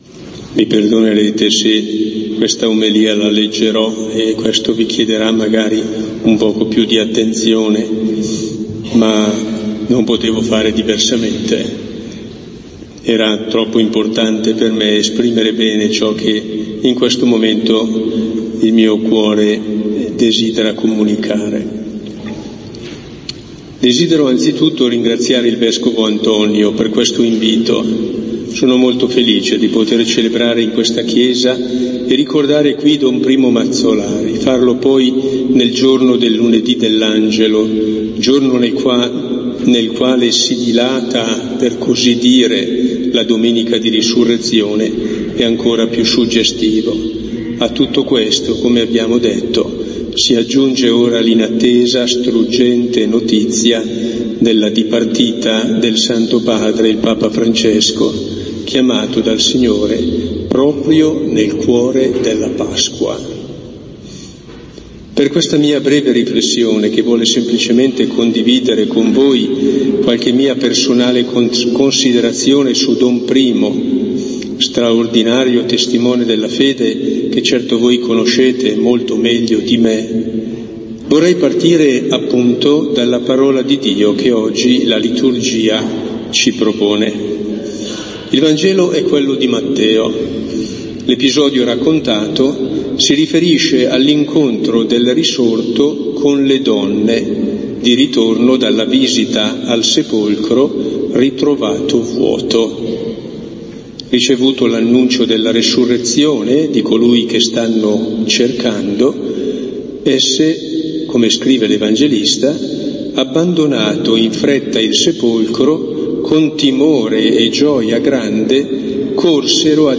La celebrazione è stata presieduta dal vescovo di Brescia Pierantonio Tremolada alla presenza del vescovo di Cremona Antonio Napolioni